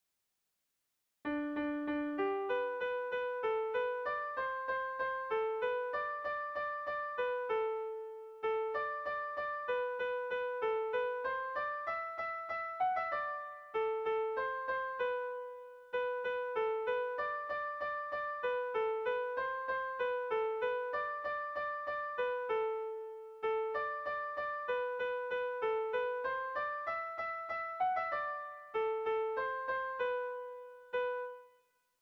Irrizkoa
Zortziko handia (hg) / Lau puntuko handia (ip)
ABDB